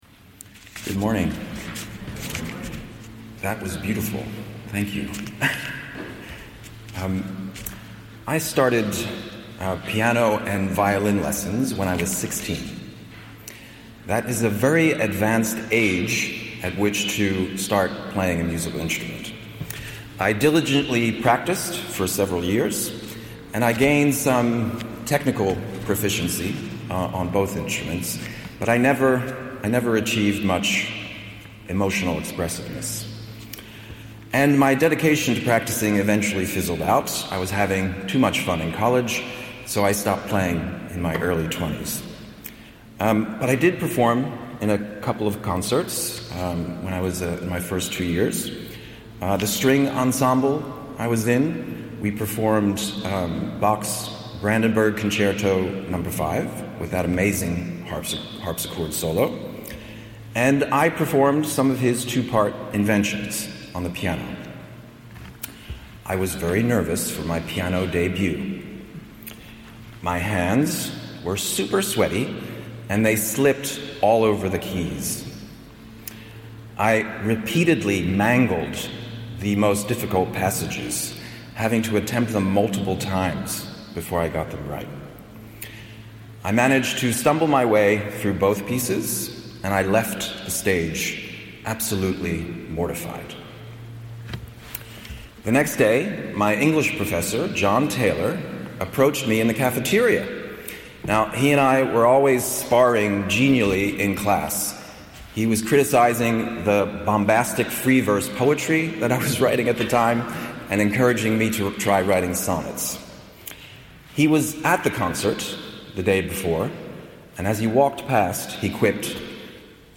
How play makes something out of nothing Harvard Memorial Church – Morning Prayers
Harvard-Memorial-Church-2025.mp3